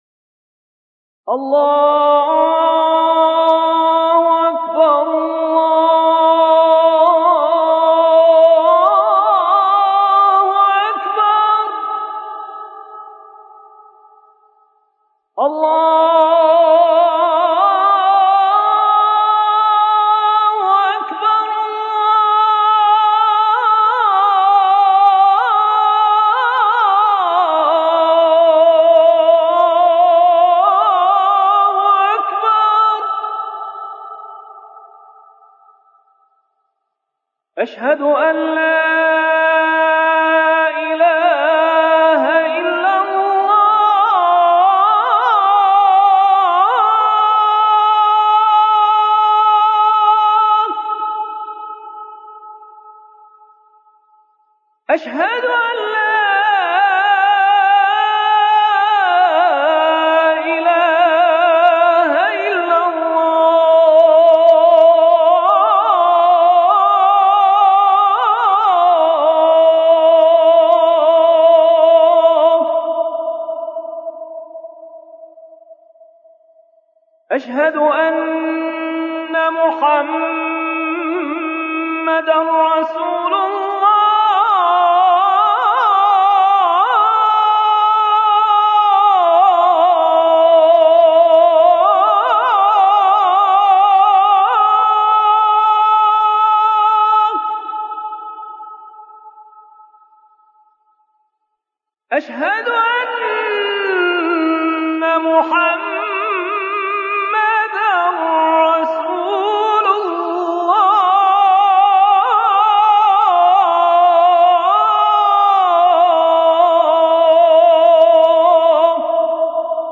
گروه فعالیت‌های قرآنی: گلبانگ اذان، با صدای دلنشین 9 قاری بین‌المللی را می‌شنوید.
این اذان در مقام «حجاز» اجراء شده است.